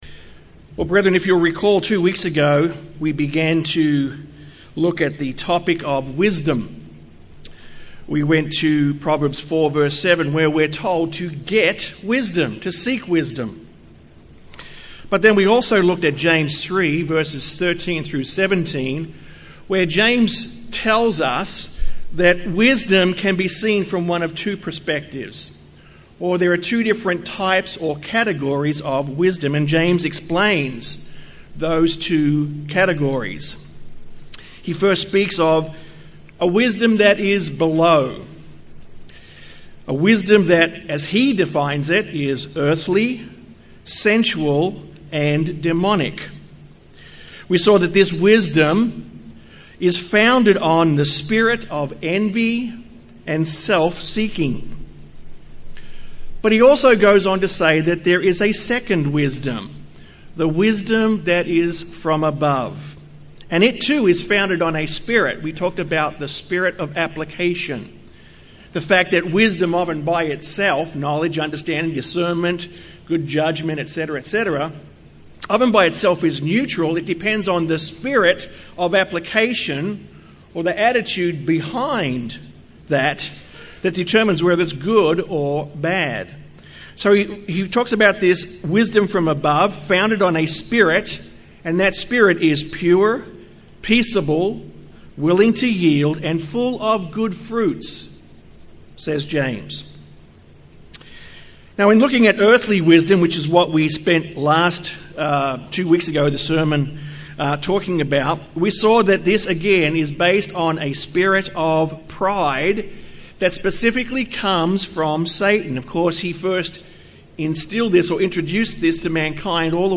This sermon continues on from Part 1 and discusses the "Wisdom From Above", or the Wisdom of God. It is this wisdom that changes the hearts and minds of men, converting them from the inside out.
Given in San Antonio, TX